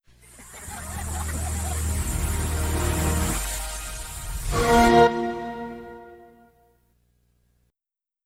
XBOX 360 (Early Kinect) Startup.wav